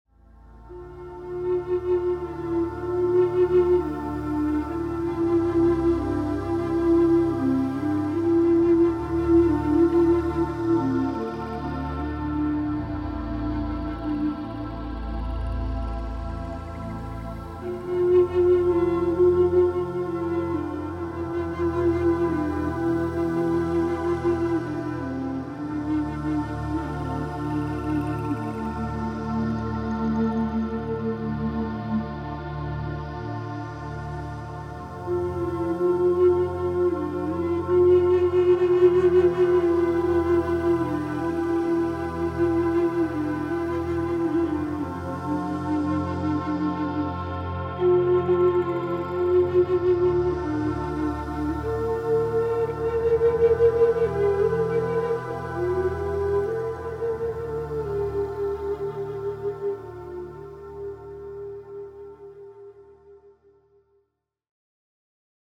Мистическая, западающая в душу музыка